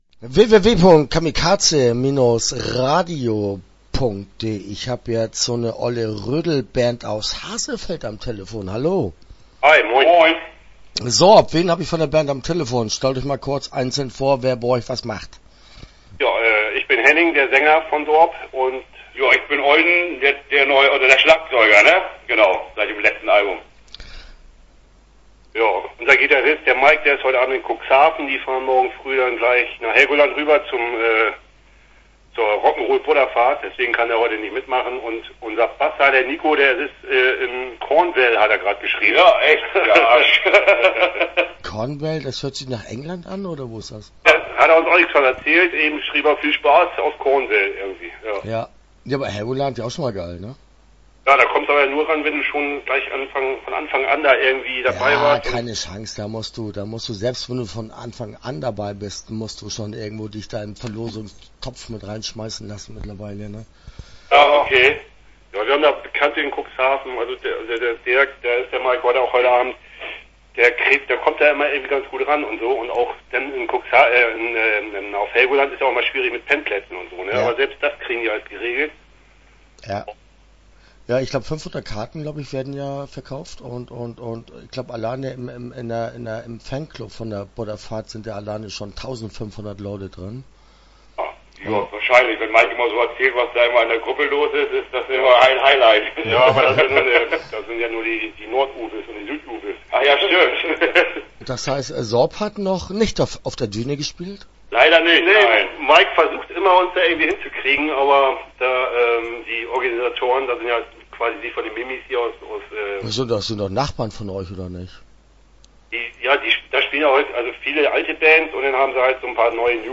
SORB - Interview Teil 1 (10:46)